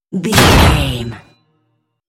Dramatic hit metal clink
Sound Effects
Atonal
heavy
intense
aggressive